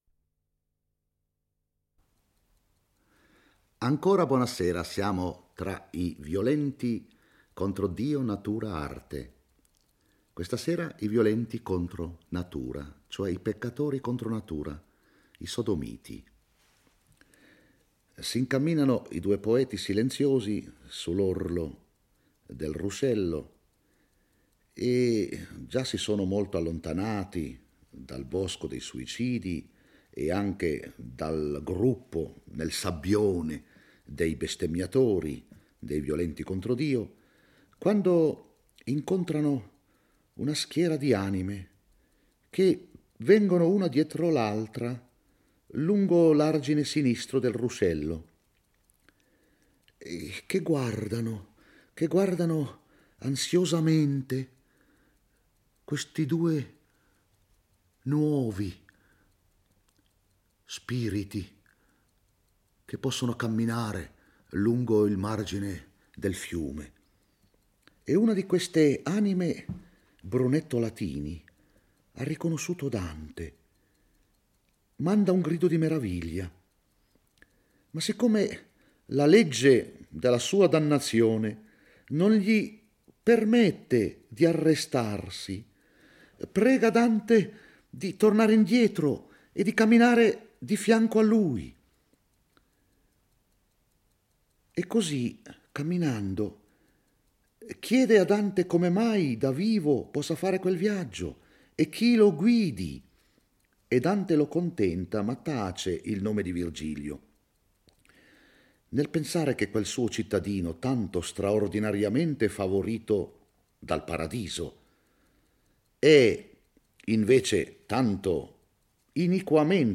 Guido Calgari legge e commenta il XV canto dell'Inferno. Dante ripercorre uno dei due argini del ruscello di sangue bollente e incontra una schiera di sodomiti che cammina sul sabbione.